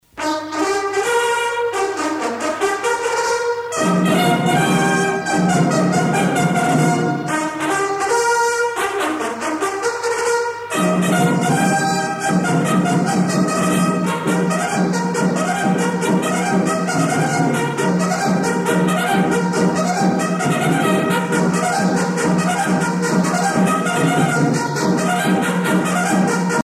à marcher
Pièce musicale éditée